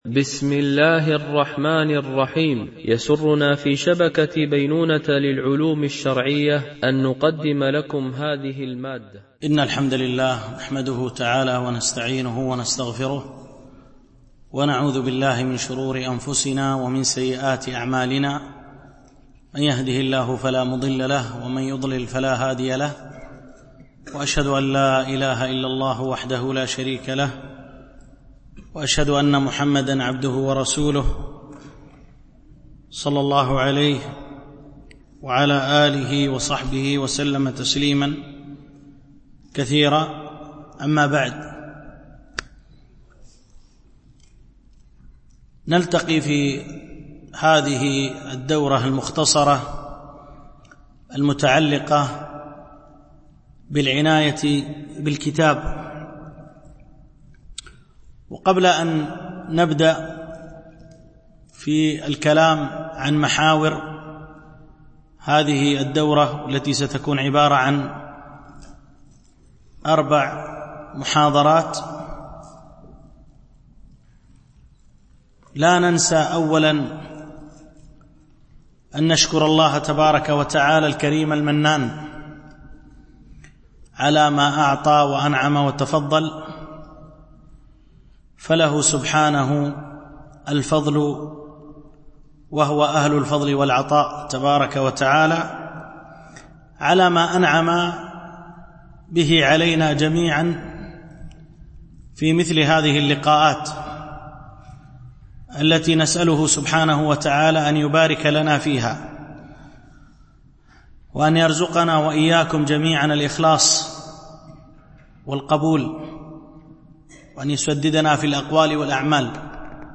دورة علمية شرعية بعنوان: العناية بالكتب نصائح وتوجيهات، بمسجد أم المؤمنين عائشة - دبي (القوز ٤)